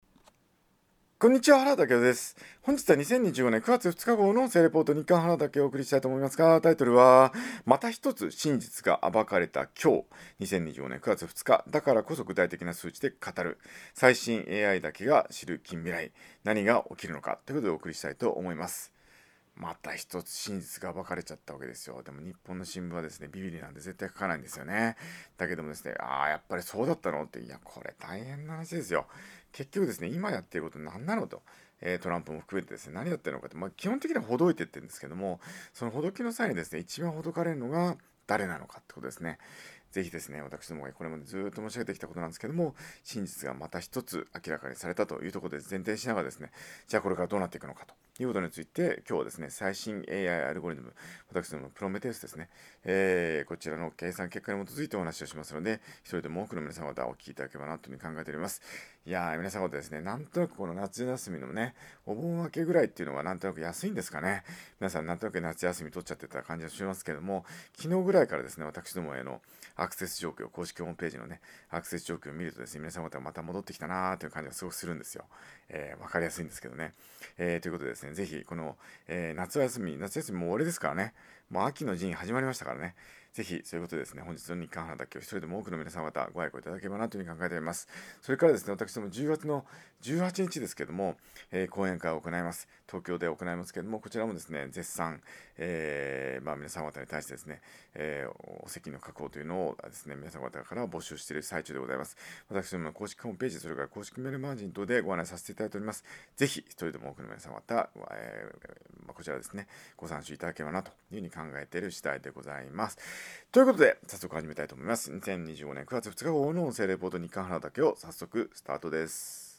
音声レポート